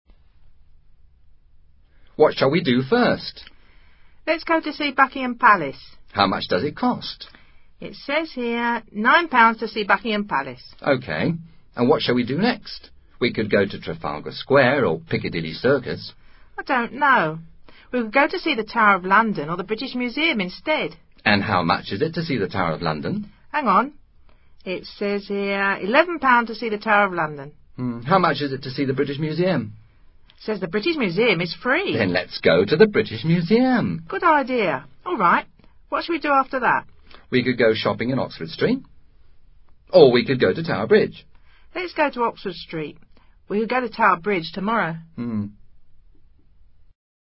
Conversación entre dos amigos que están recorriendo Londres.